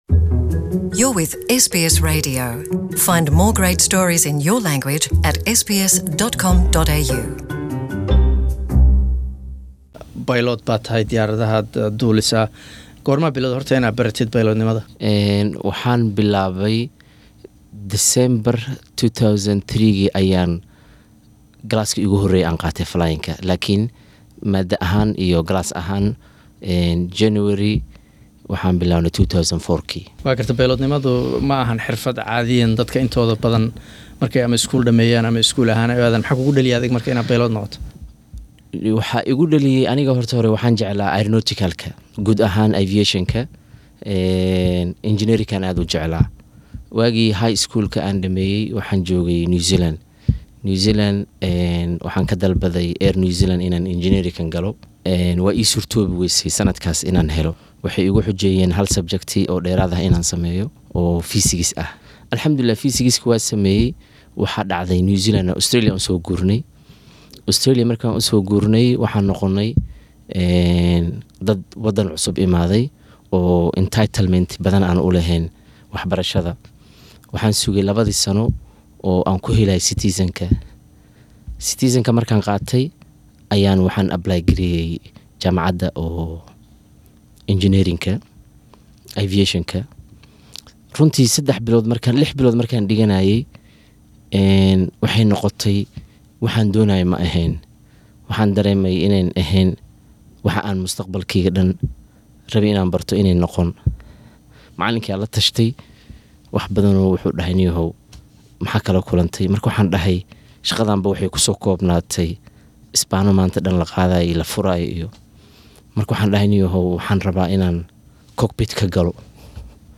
Interview
Waraysi